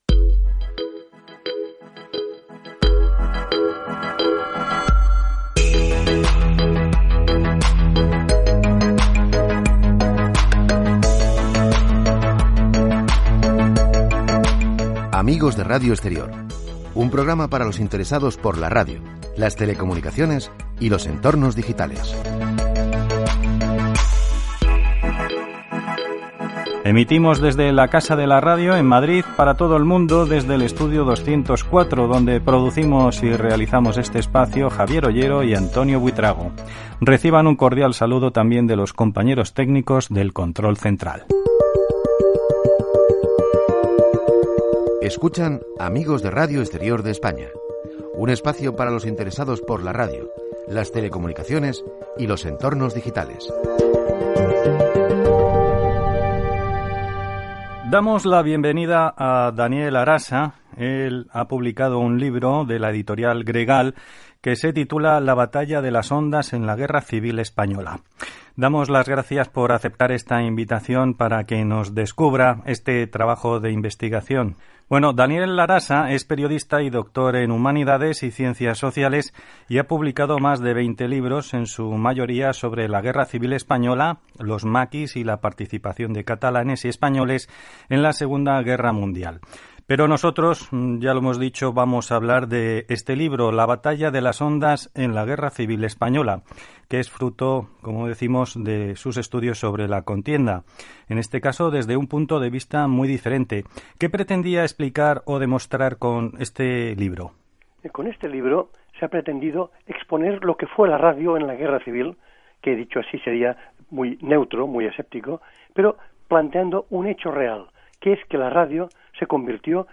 Careta del programa, equip, indicatiu, entrevista
careta de sortida i comiat